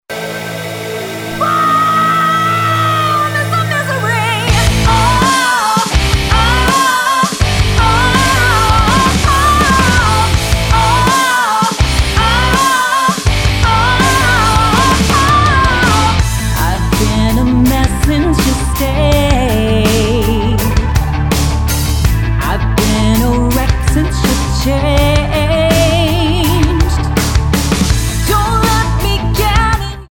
--> MP3 Demo abspielen...
Tonart:Am Multifile (kein Sofortdownload.
Die besten Playbacks Instrumentals und Karaoke Versionen .